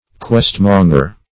Search Result for " questmonger" : The Collaborative International Dictionary of English v.0.48: Questmonger \Quest"mon`ger\, n. One who lays informations, and encourages petty lawsuits.